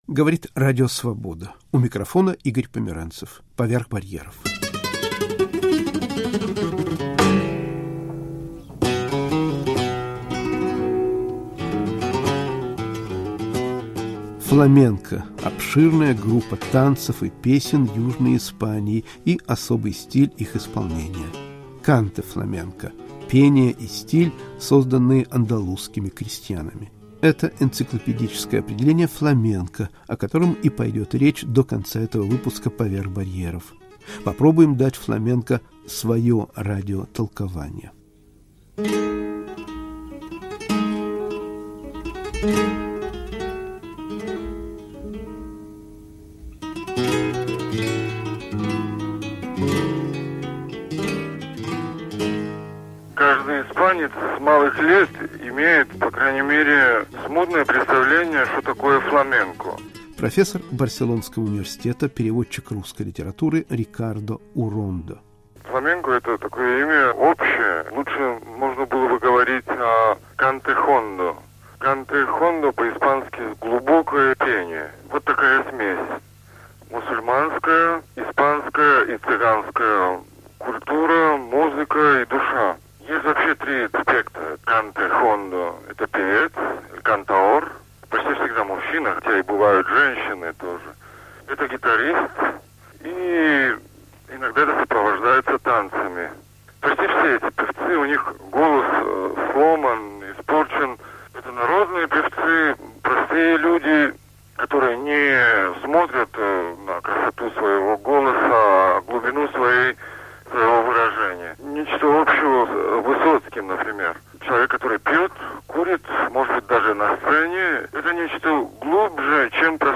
Фламенко